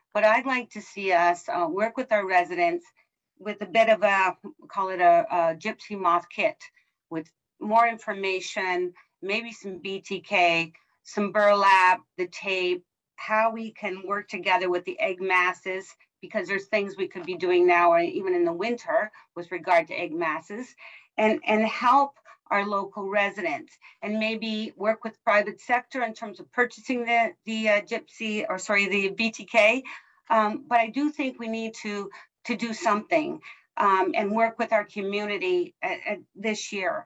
At Monday’s Quinte West council meeting, council heard the results of a detailed study conducted by BioForest of the damage projected to be caused by the moths in 2022.
Councillor Karen Sharpe proposed that the municipality consider ways to work with Quinte West residents on the problem.